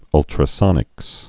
(ŭltrə-sŏnĭks)